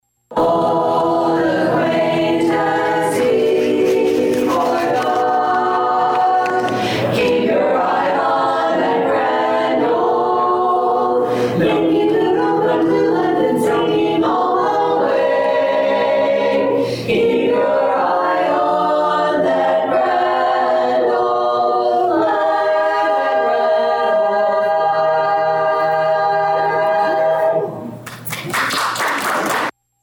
{The Danville Sweet Adelines perform during Sunday’s event at the Vermilion County Museum.}